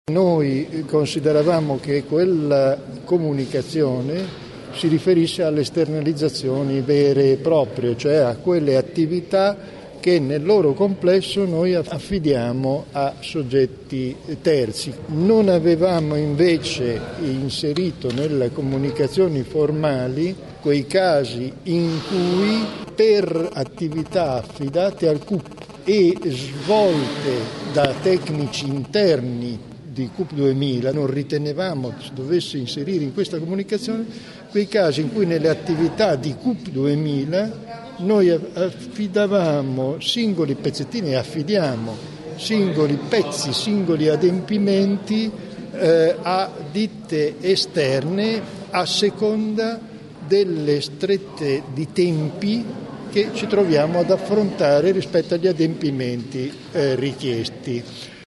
Vertici del Cup sentiti in Commissione Bilancio